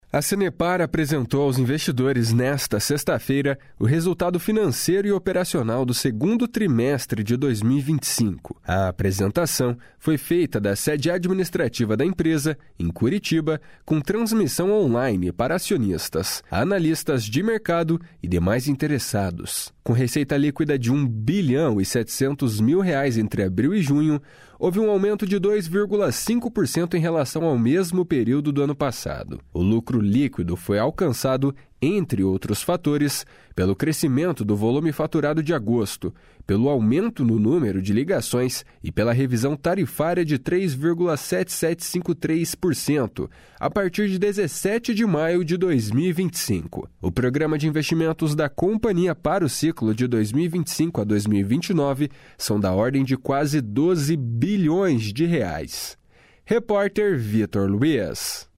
Repórter: